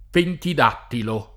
pentid#ttilo] (Cal.)